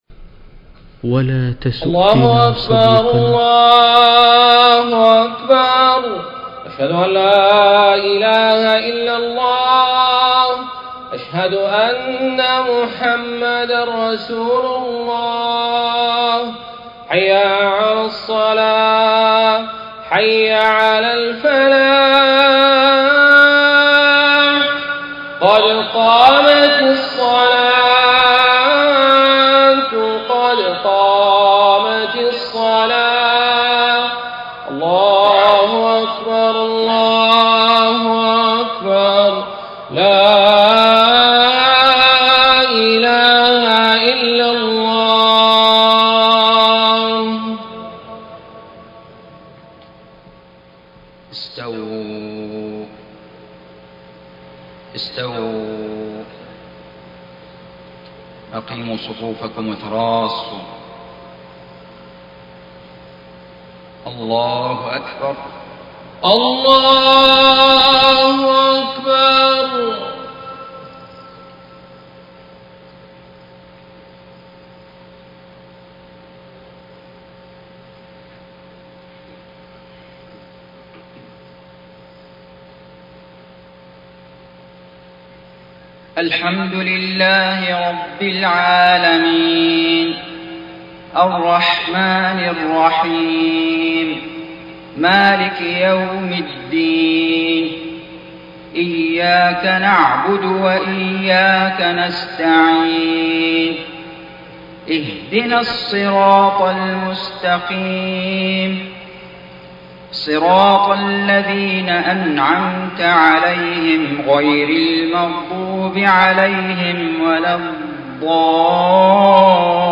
صلاة الفجر 21 شوال 1430هـ فواتح سورة غافر 1-20 > 1430 🕋 > الفروض - تلاوات الحرمين